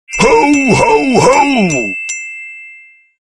Cell Phone Notification Sounds in MP3 Format
Ho! Ho! Ho!